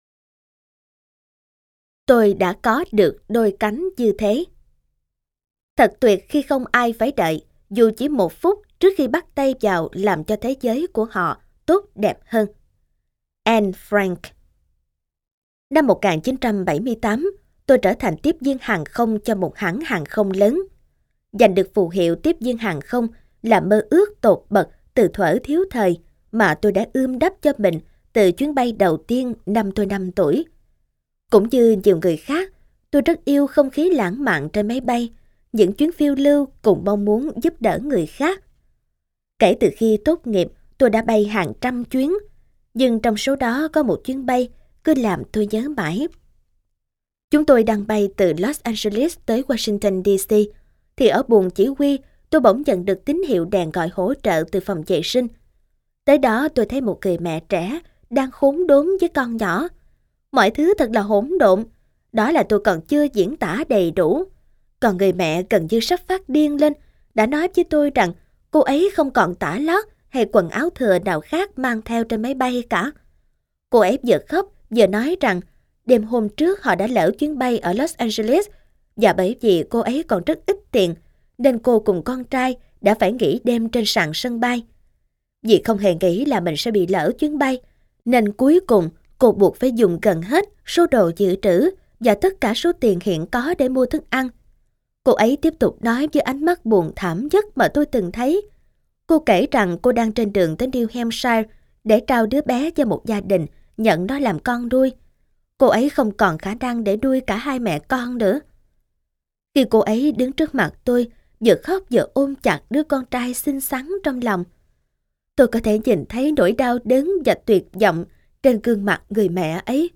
Sách nói Chicken Soup 25 - Trải Nghiệm Từ Những Chuyến Đi - Jack Canfield - Sách Nói Online Hay